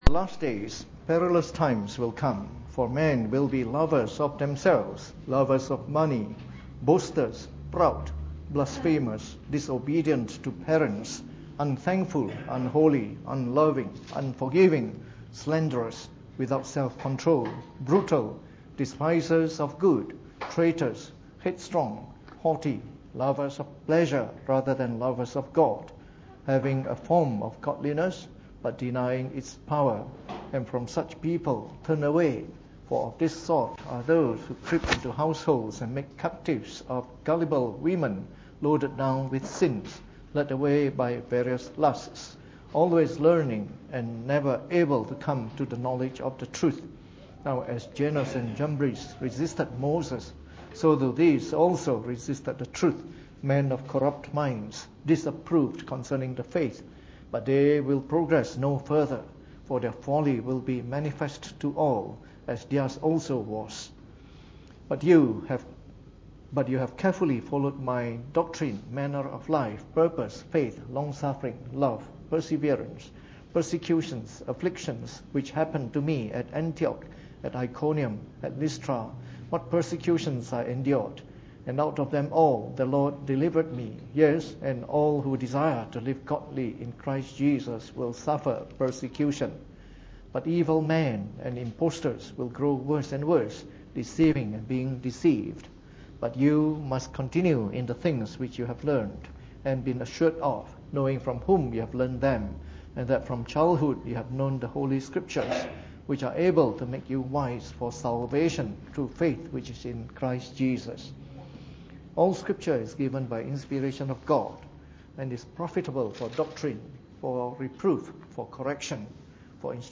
Preached on the 19th of October 2016 during the Bible Study, from our series on the Five Principles of the Reformed Faith.